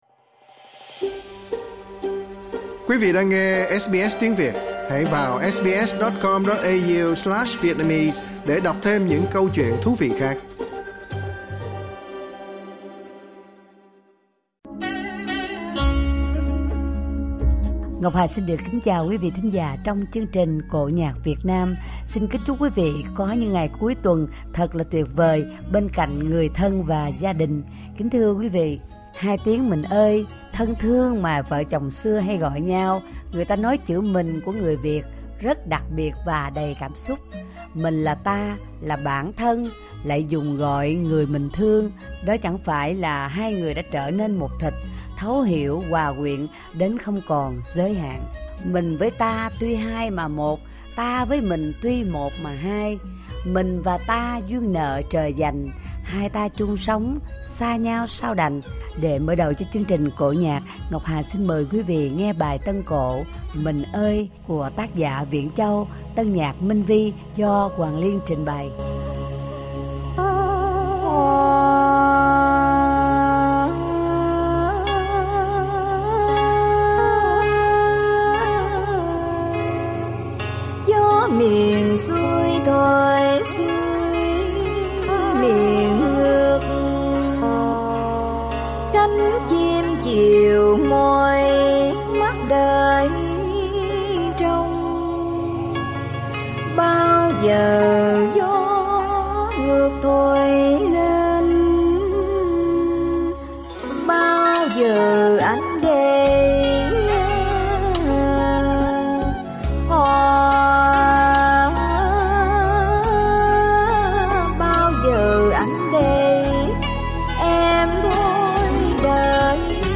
bài tân cổ